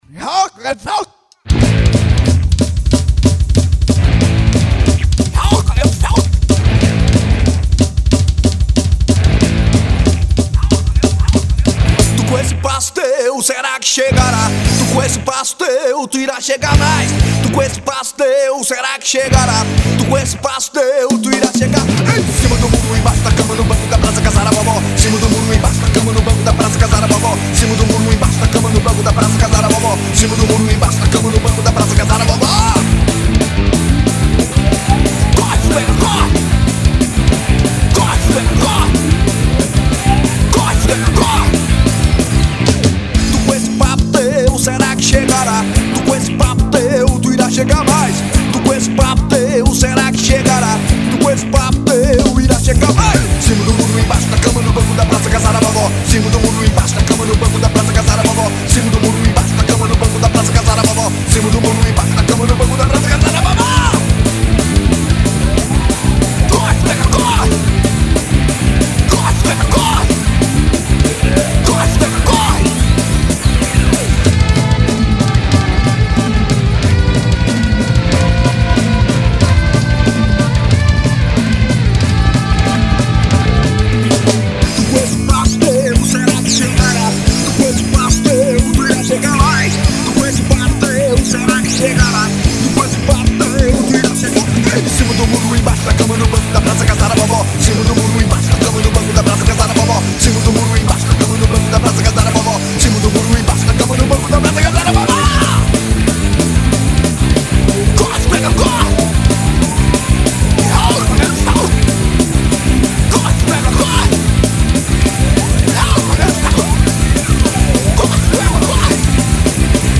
1959   02:17:00   Faixa:     Rock Nacional